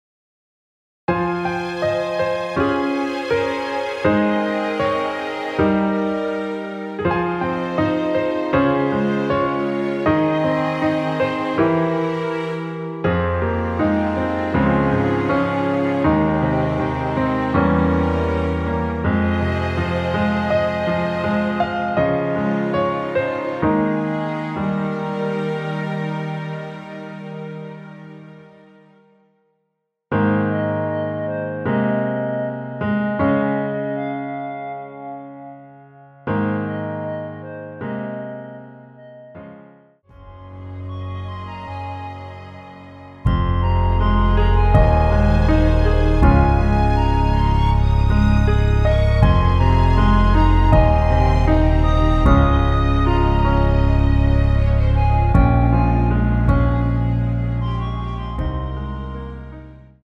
원키 멜로디 포함된 MR입니다.
Ab
앞부분30초, 뒷부분30초씩 편집해서 올려 드리고 있습니다.
중간에 음이 끈어지고 다시 나오는 이유는
(멜로디 MR)은 가이드 멜로디가 포함된 MR 입니다.